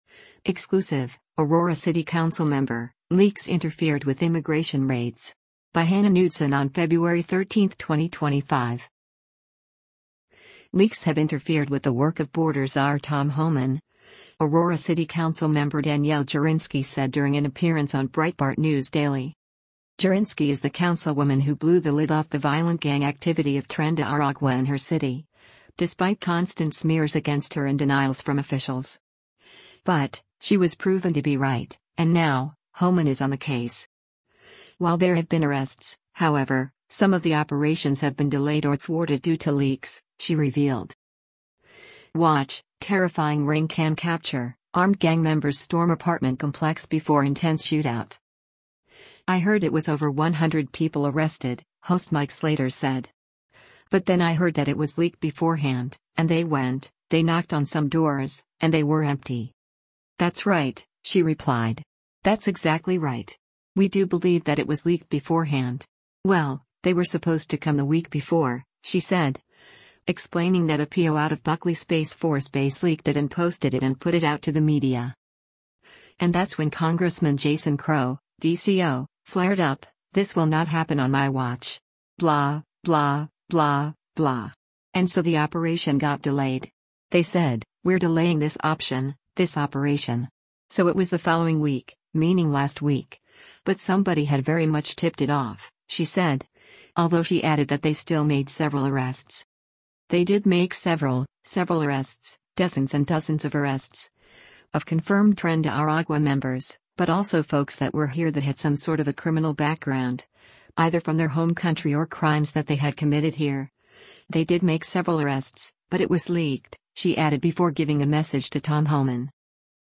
Leaks have interfered with the work of border czar Tom Homan, Aurora City Council Member Danielle Jurinsky said during an appearance on Breitbart News Daily.